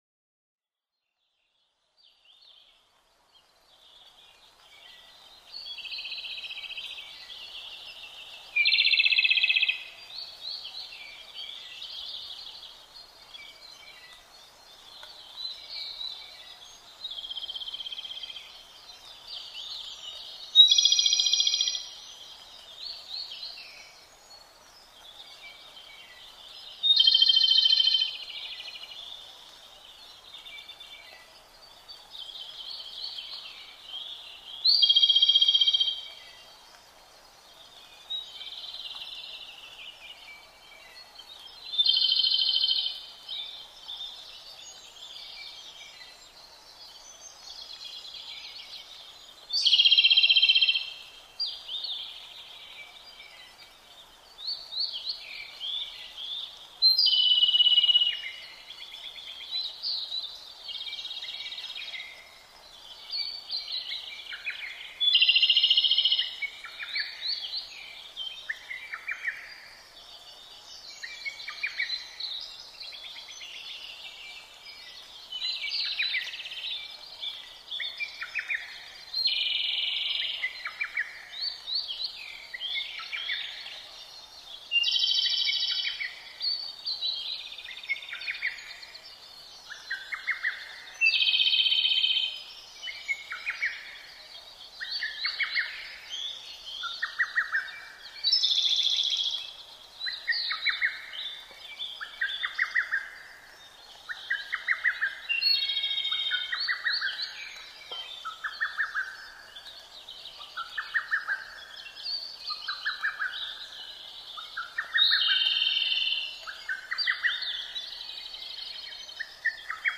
コマドリ　Erithacus akahigeツグミ科
日光市稲荷川上流　alt=1330m
Mic: built-in Mic.
他の自然音：アカハラ、ホトトギス、ゴジュウカラ、タゴガエル